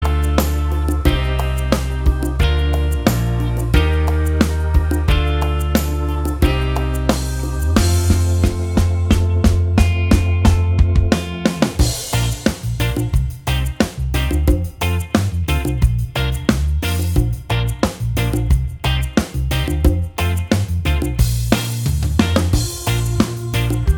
Minus Lead Guitar Pop (2010s) 4:28 Buy £1.50